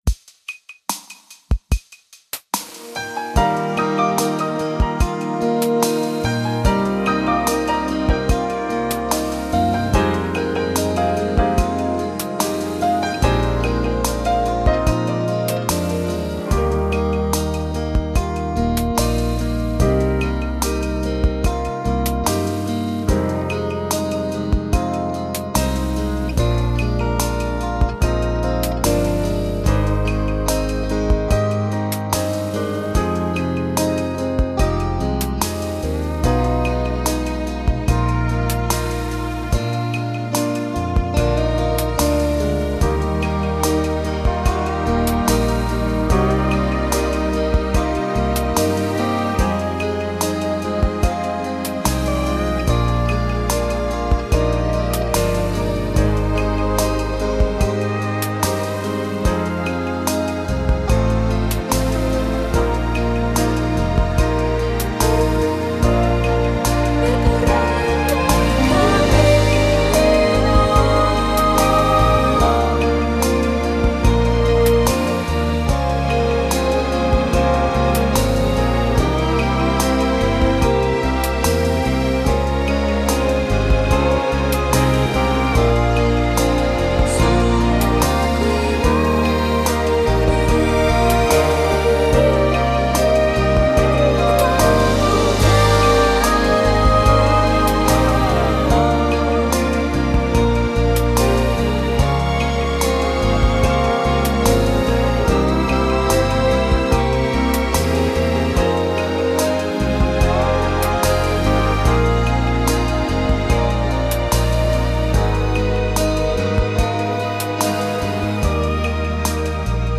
Genere: Lento
Scarica la Base Mp3 (3,72 MB)